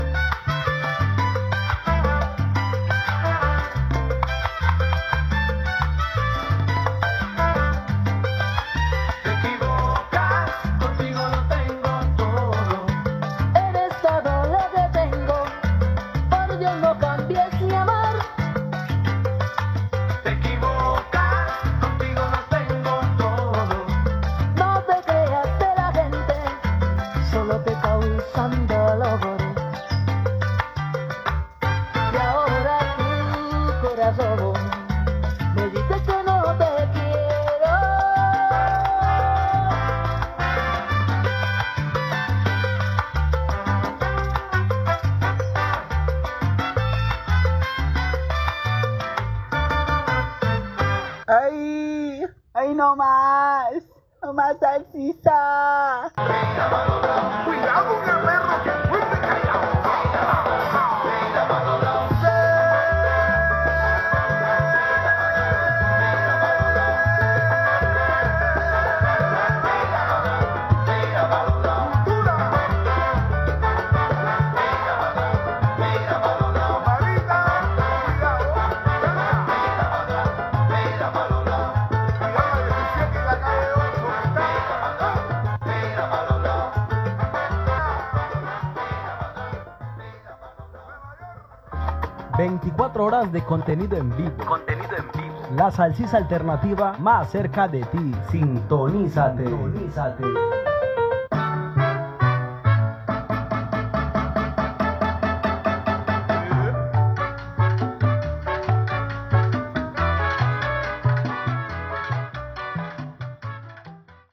Música, identificació, música i indicatiu